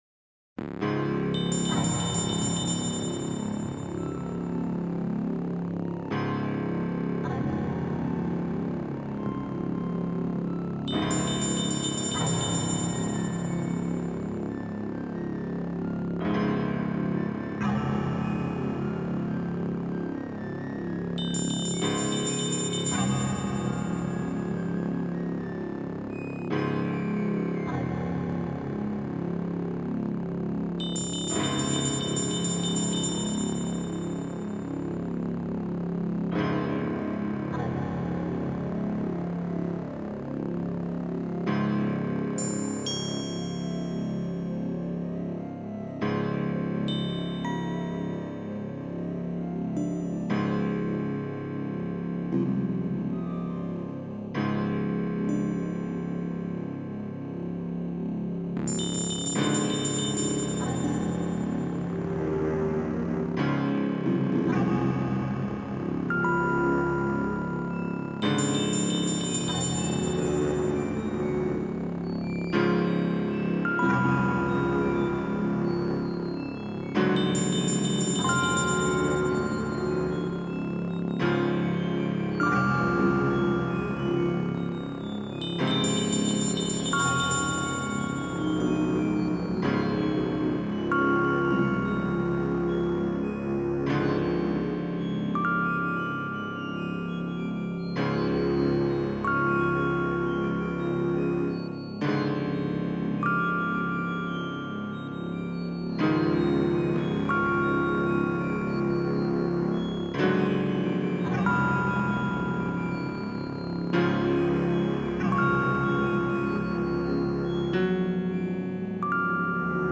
BGM Music.